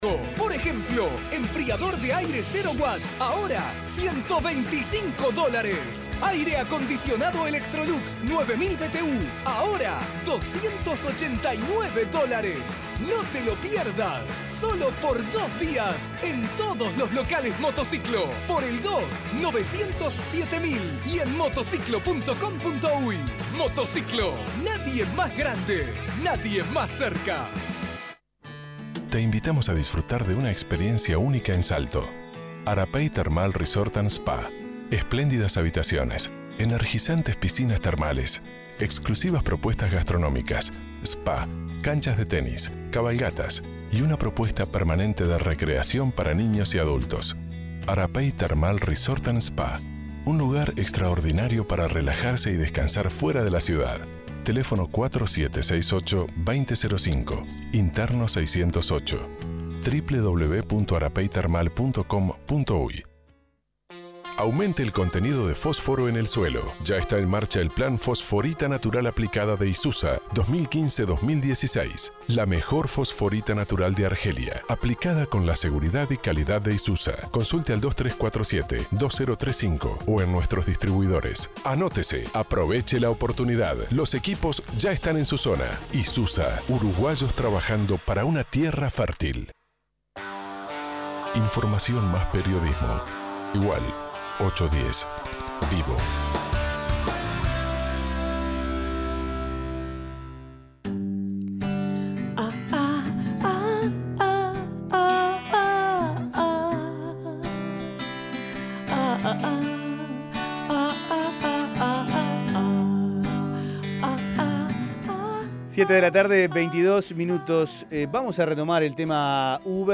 Escuche al diputado Felipe Carballo
Carballo dijo en diálogo con 810 Vivo que la idea es legislar para todo el país.